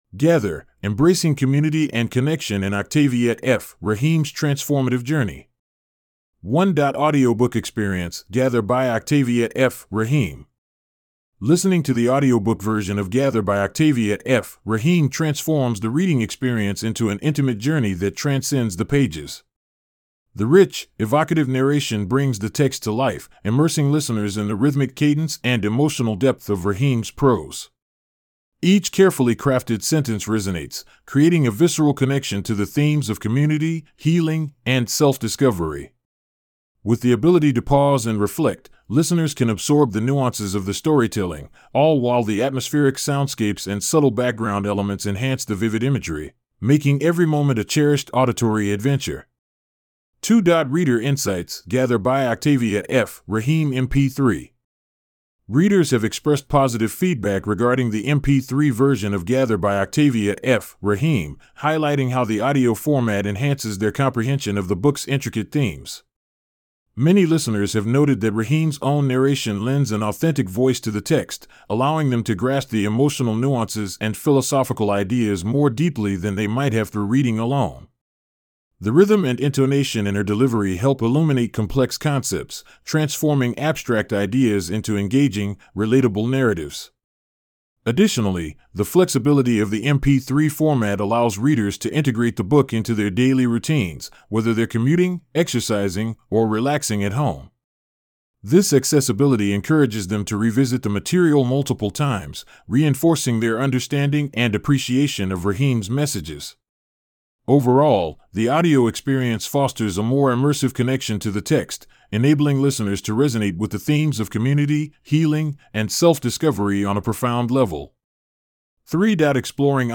With the ability to pause and reflect, listeners can absorb the nuances of the storytelling, all while the atmospheric soundscapes and subtle background elements enhance the vivid imagery, making every moment a cherished auditory adventure.